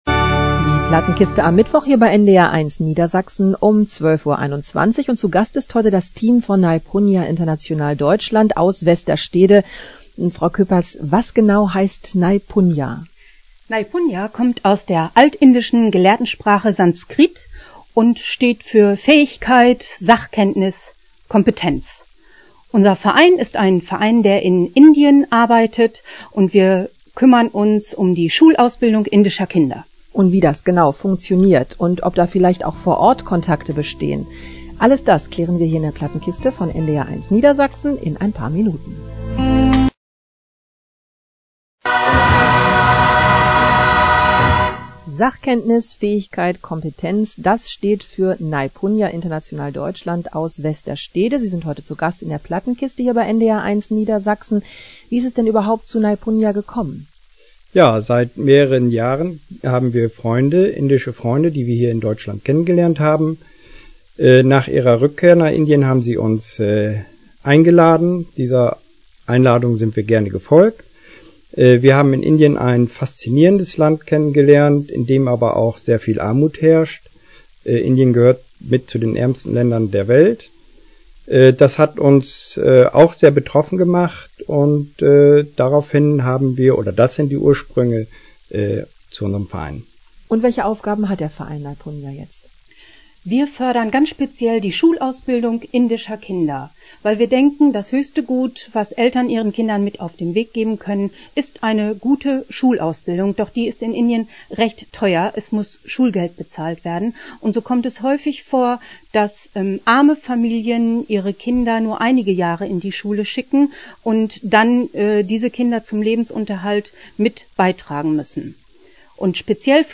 Radioauftritt am 14.Dezember 2005 in der NDR1 Plattenkiste:
In der Livesendung erhielten wir die Möglichkeit, über unseren Verein zu berichten.
Interview.mp3